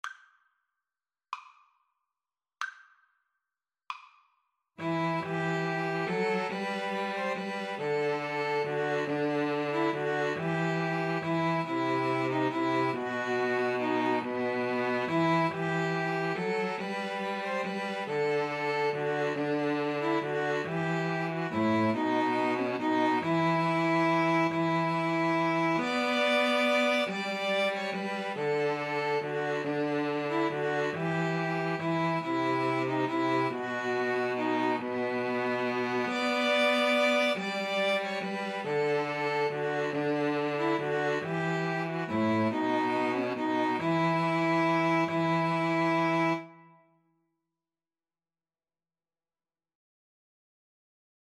ViolinCelloPiano